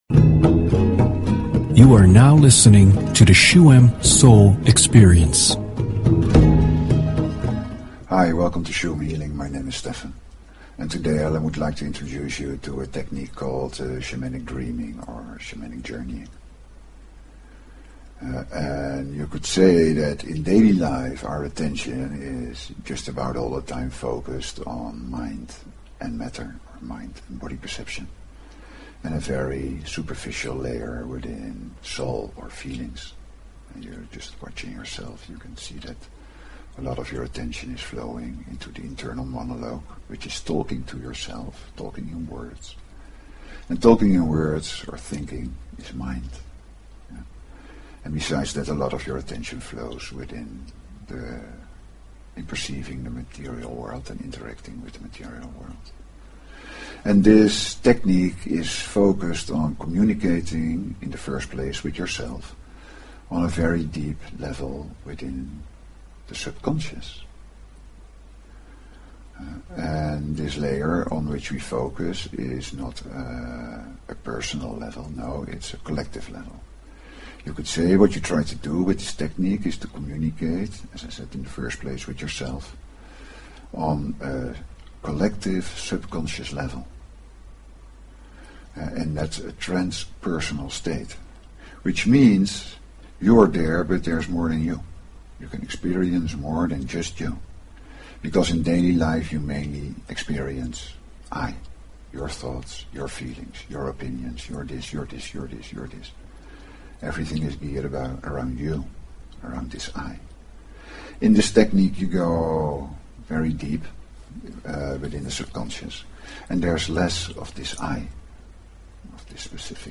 Talk Show Episode, Audio Podcast, Shuem_Soul_Experience and Courtesy of BBS Radio on , show guests , about , categorized as
The second part of the show is a shamanic healingmeditation in which sounds of drum, rattle and chant help to calm the mind and experience wider states of being.